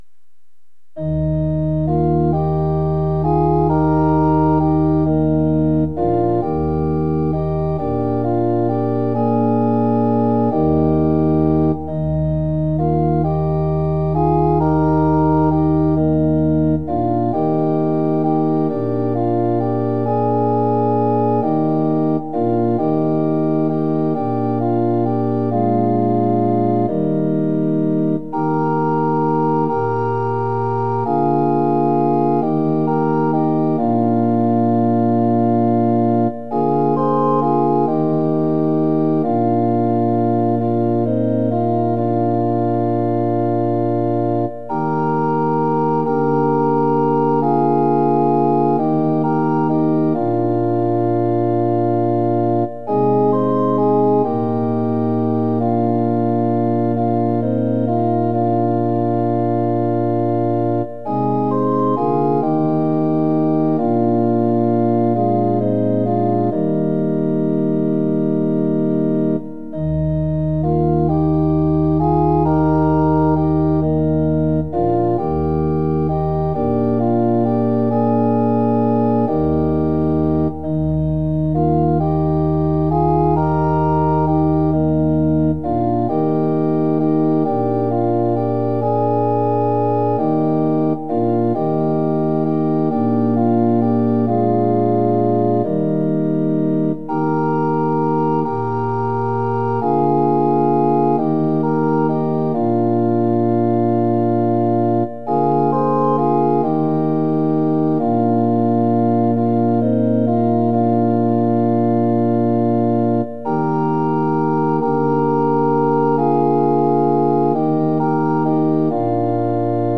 ◆　８分の６拍子：　１拍目から始まります。